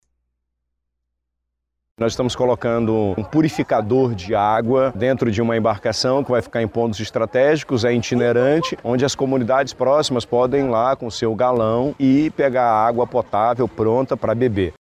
A estação tem capacidade de armazenamento de 30 mil litros de água por dia e funciona em uma balsa equipada, explica o governador do Estado, Wilson Lima.